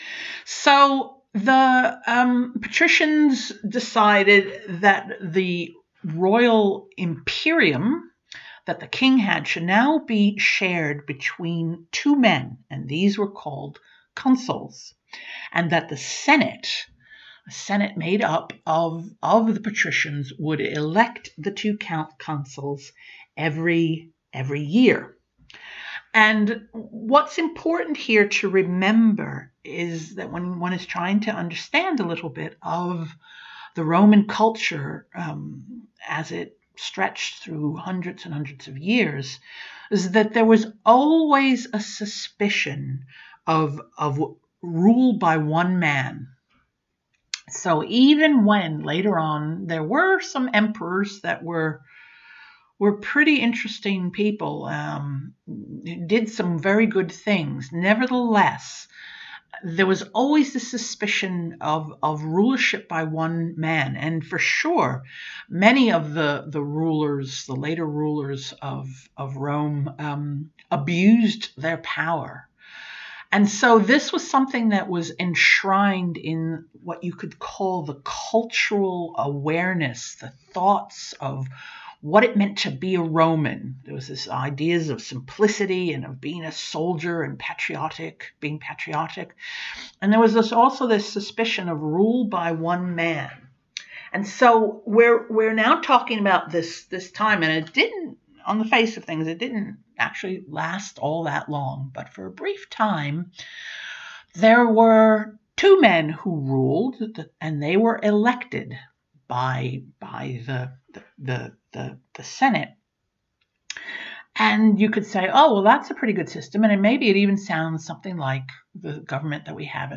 Here are two short excerpts of talks on Roman and Medieval history:
Roman history excerpt
Roman-talk-preview.mp3